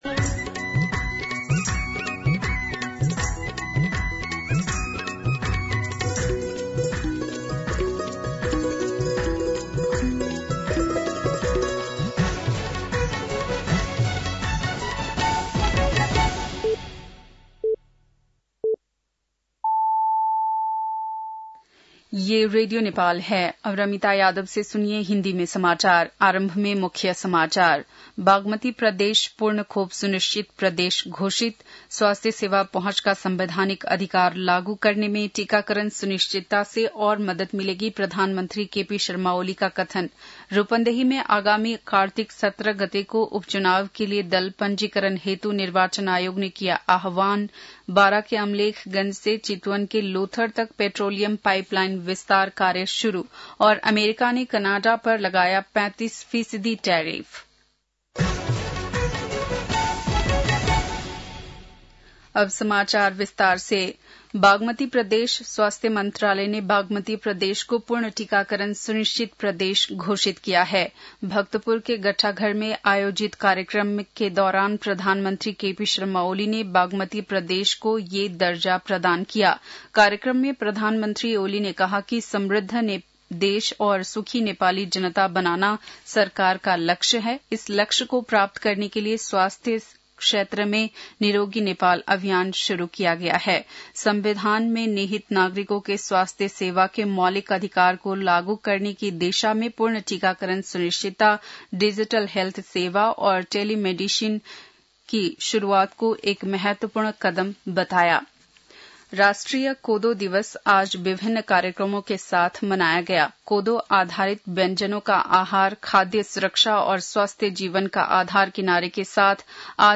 बेलुकी १० बजेको हिन्दी समाचार : १६ साउन , २०८२
10-PM-Hindi-NEWS-4-16.mp3